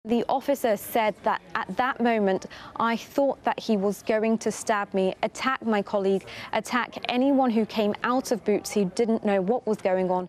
from outside the Royal Courts of Justice